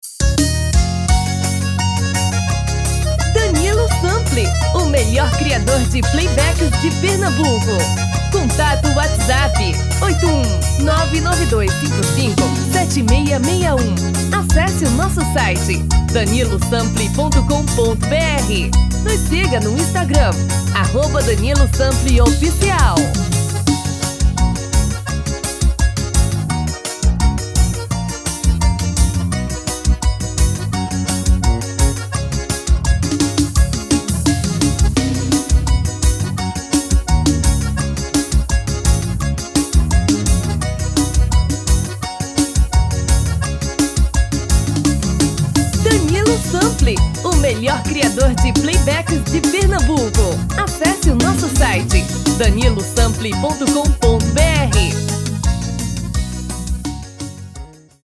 DEMO 1: tom original DEMO 2: tom feminino